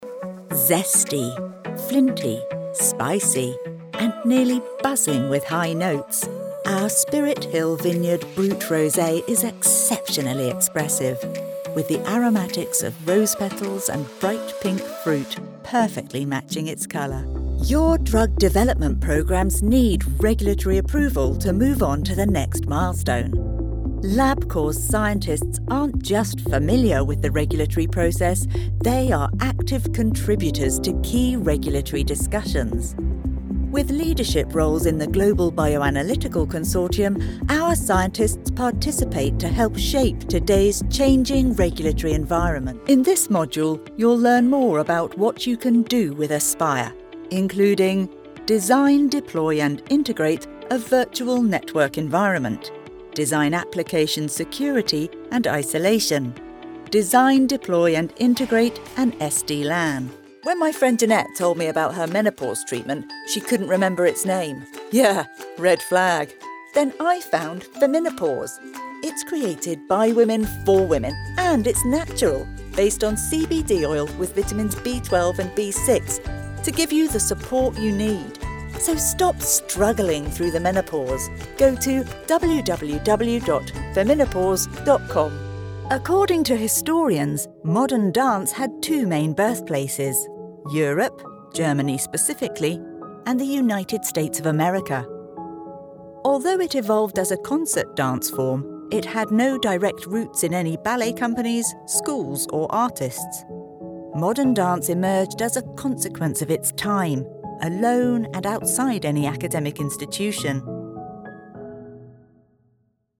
Mature, educated, warm and engaging British English voiceover.
Sprechprobe: Sonstiges (Muttersprache):
Mature, educated, warm and engaging British English voice artist.